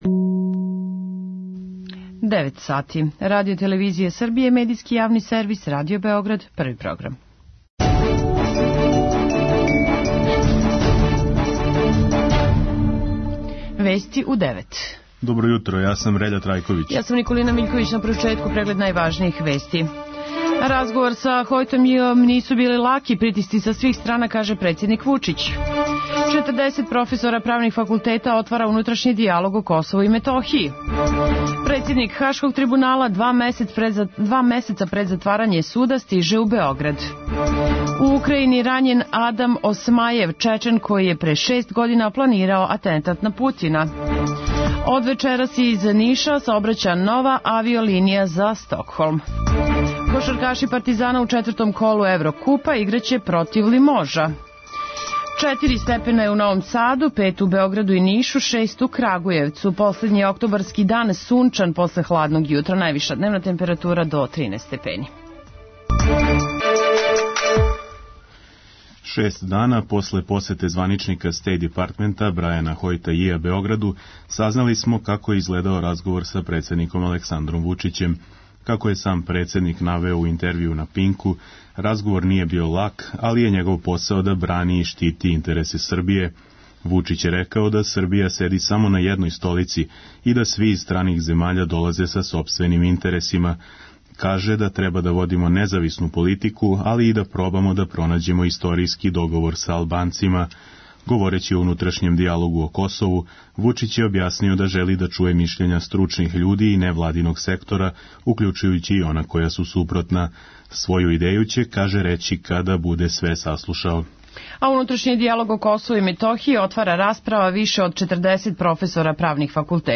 преузми : 3.65 MB Вести у 9 Autor: разни аутори Преглед најважнијиx информација из земље из света.